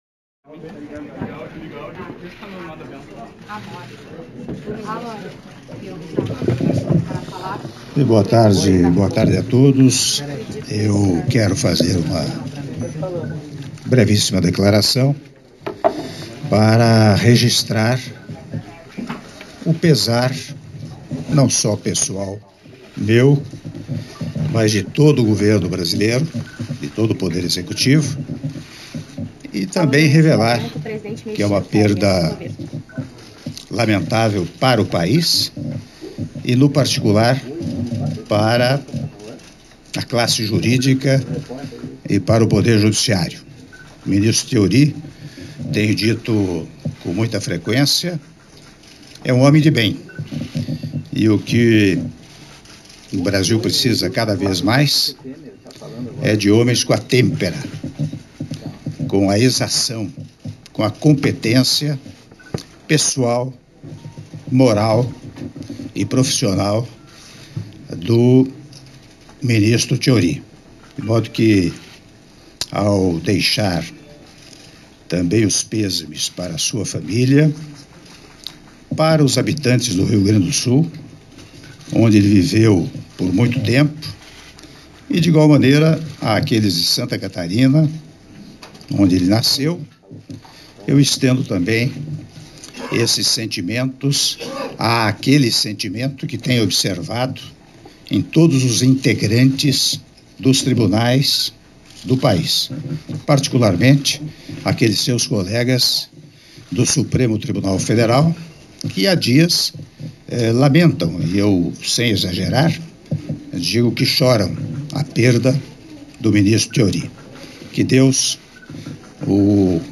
Áudio da declaração à Imprensa do Presidente da República, Michel Temer, após o velório do ministro do Supremo Tribunal Federal, Teori Zavascki - (02min40s) - Porto Alegre/RS — Biblioteca